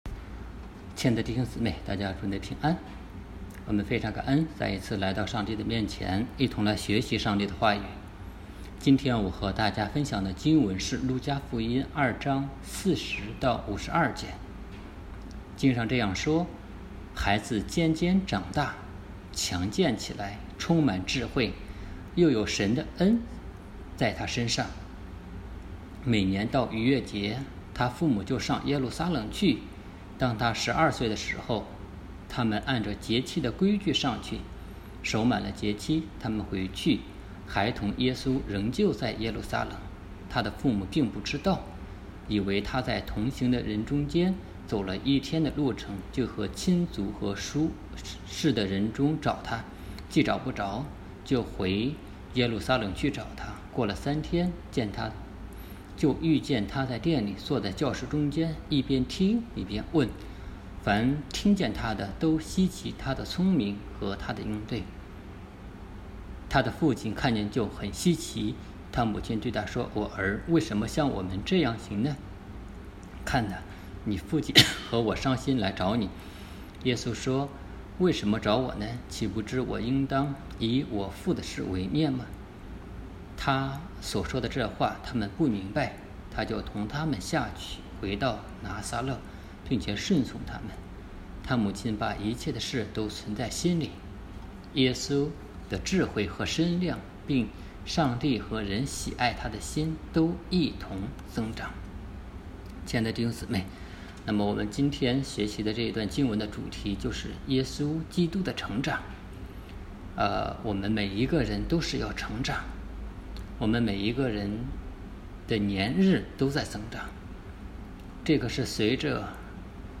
证道